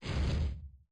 118-Fire02.opus